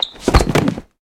anonDumbassFall.ogg